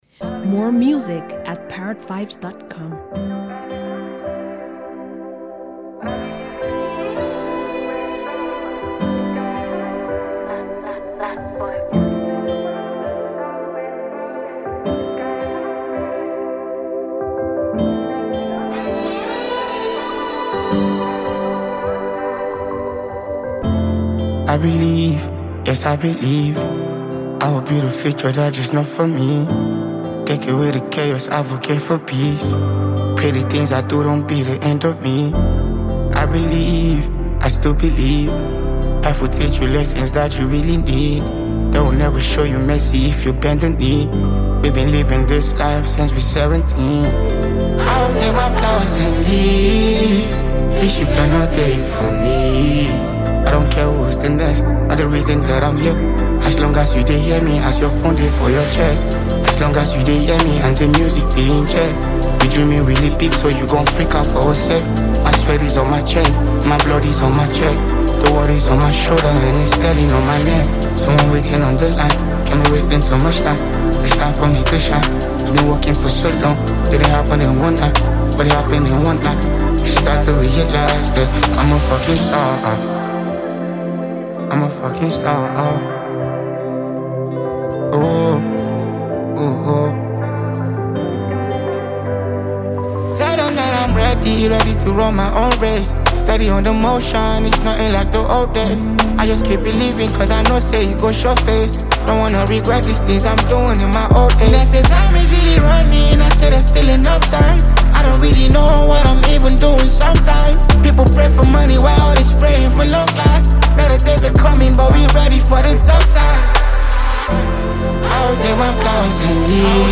Rapidly rising Nigerian Afro-pop music genius and songwriter